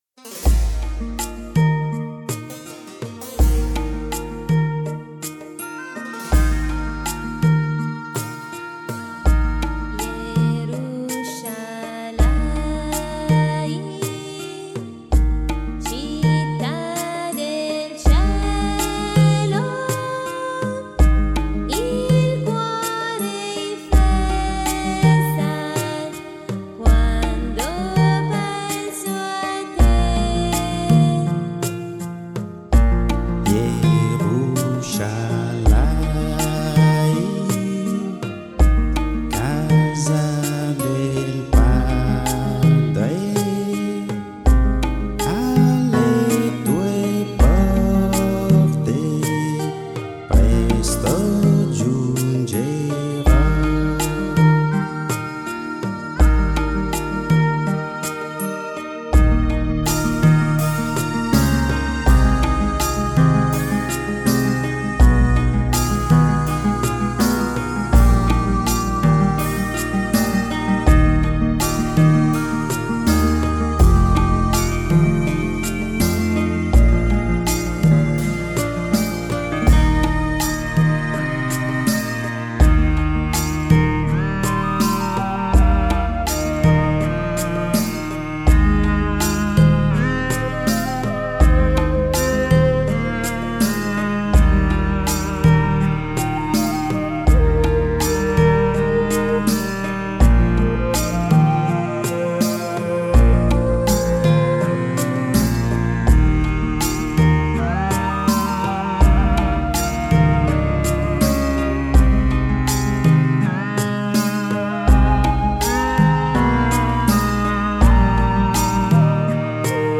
Canto per Rosario e Parola di Dio: Jerushalaim